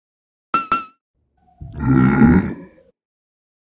fire-2.ogg.mp3